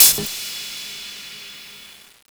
Closed Hats
HHAT - FEEDER.wav